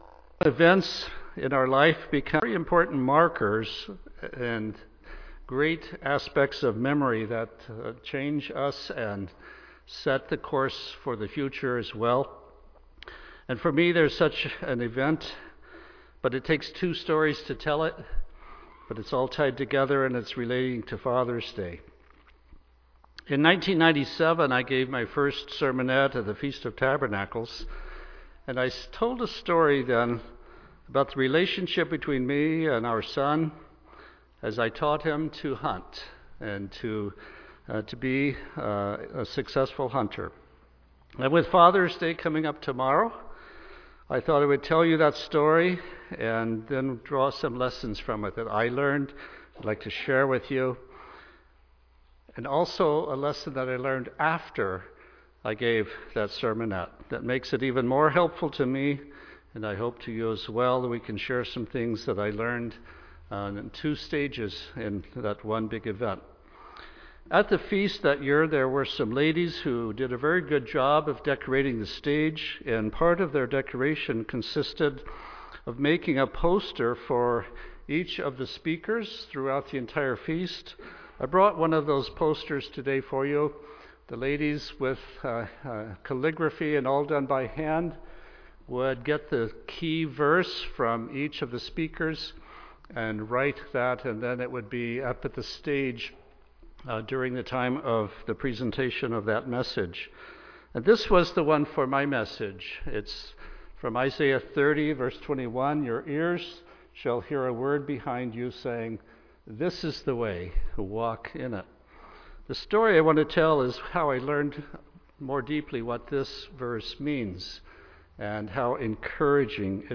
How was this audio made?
Given in Tacoma, WA